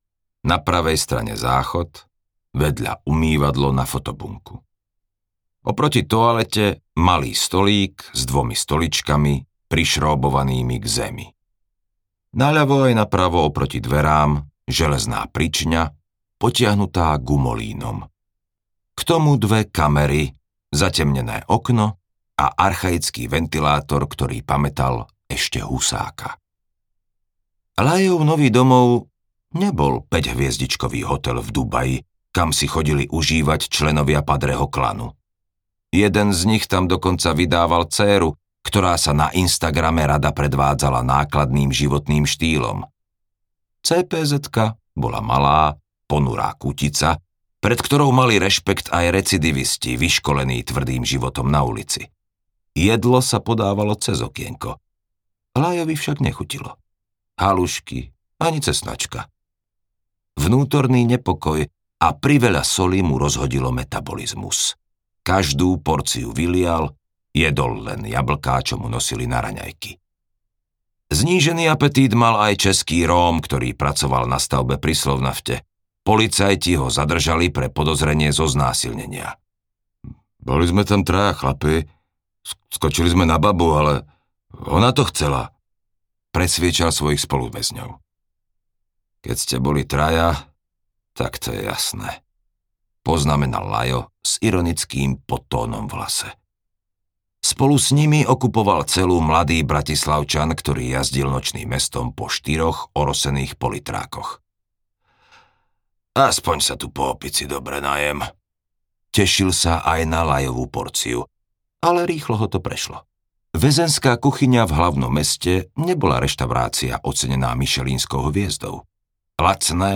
Padreho klan I + II audiokniha
Ukázka z knihy